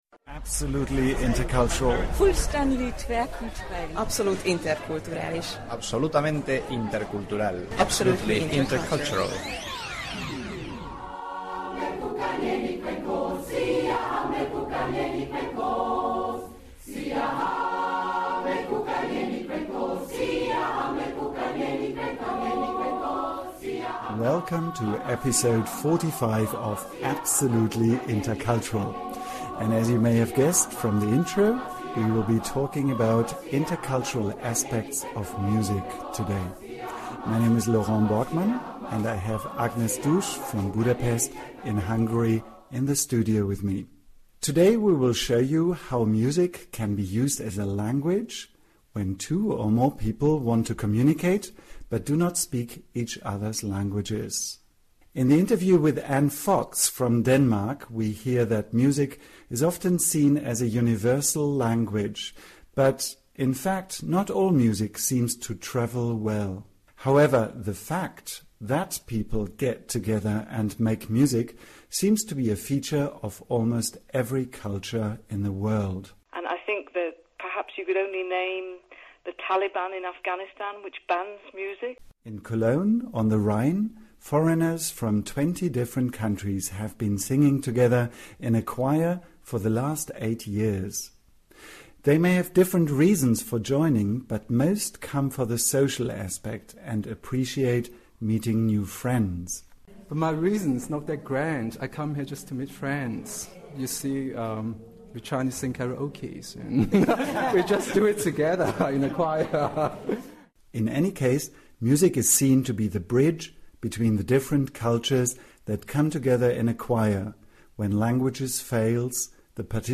We will also listen to their music!